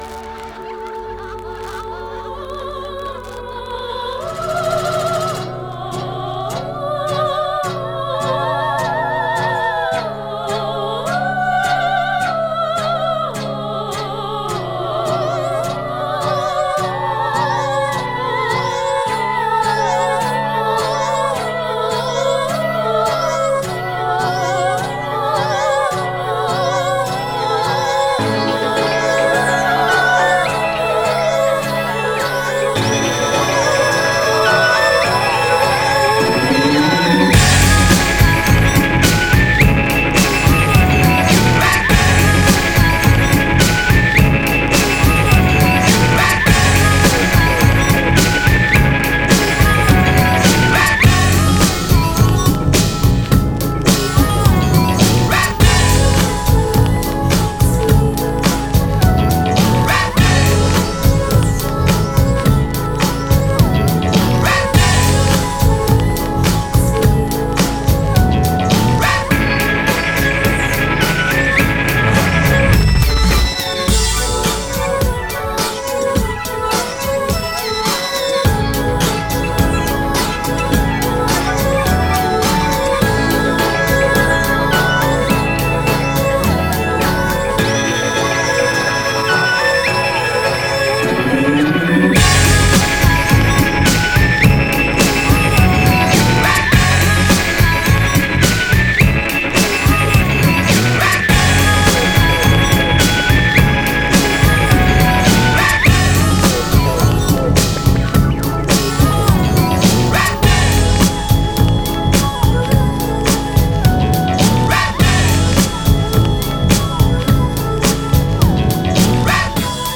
Жанр: electronic, hip hop, downtempo